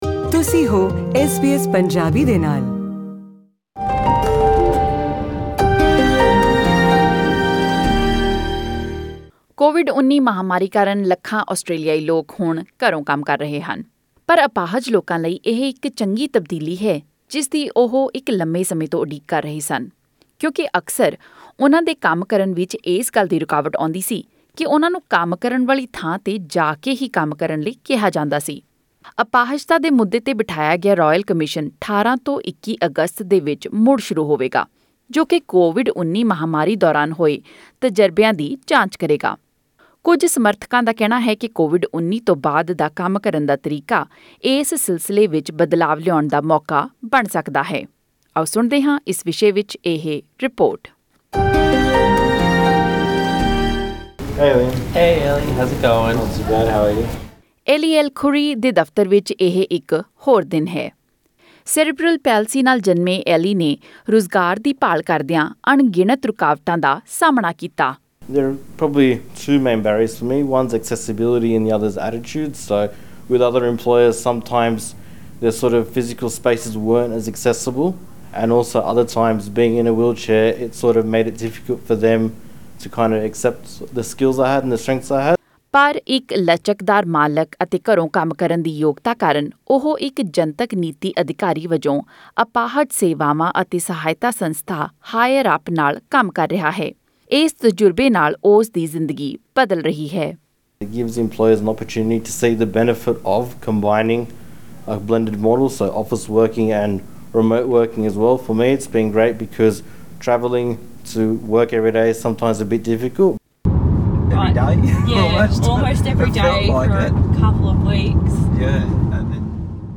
To hear the full report click on the audio link above.